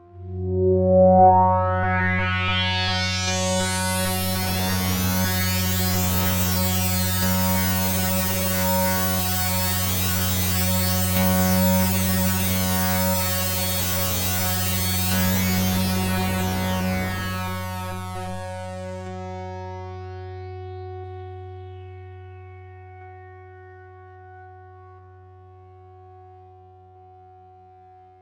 标签： F4 MIDI音符-66 Korg的-Z1 合成器 单票据 多重采样
声道立体声